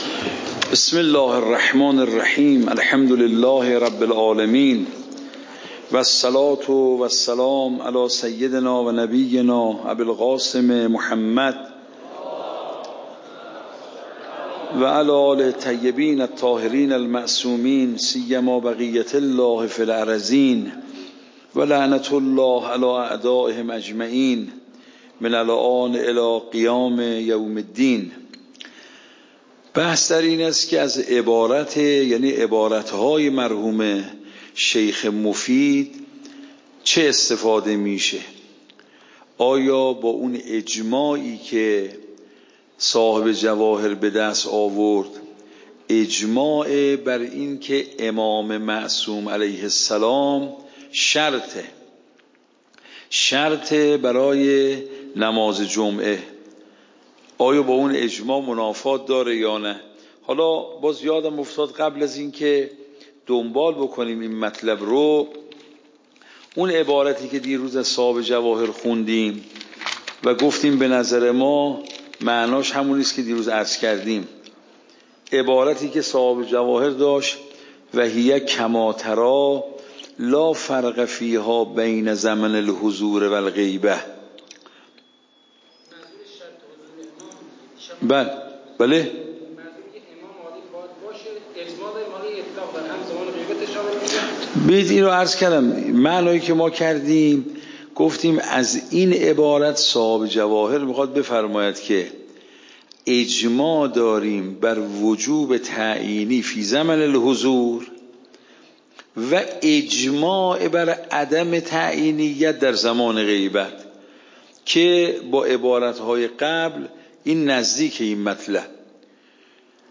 صوت درس